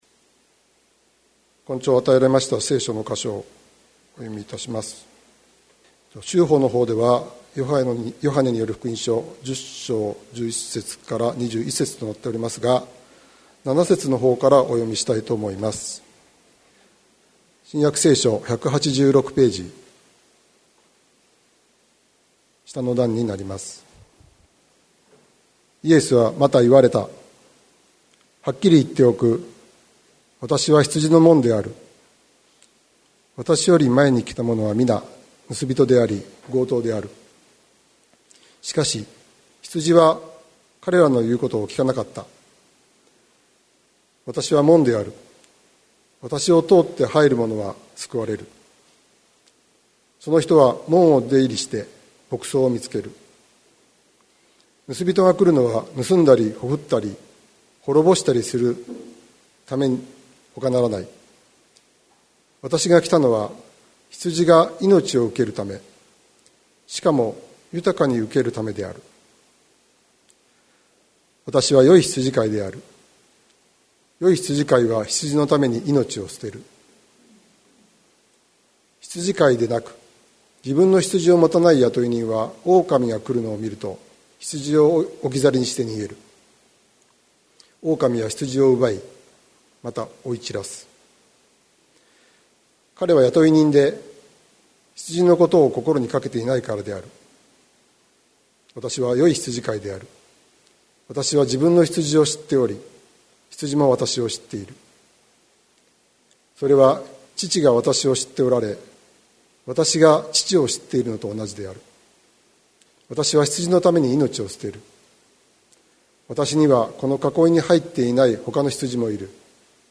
2022年11月06日朝の礼拝「いつの日かひとつになる」関キリスト教会
説教アーカイブ。